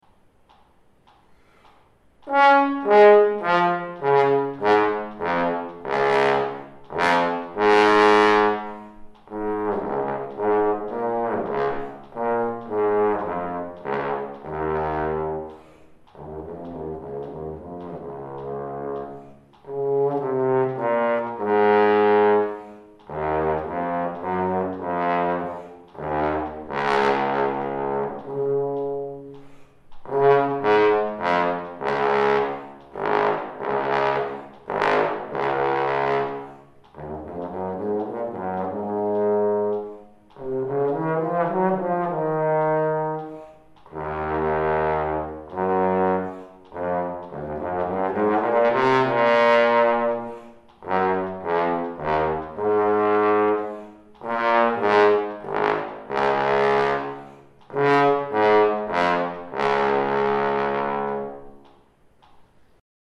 Blume/Fink – 36 Studies for Trombone with F Attachment